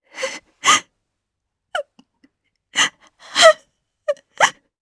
Isaiah-Vox_Sad_jp.wav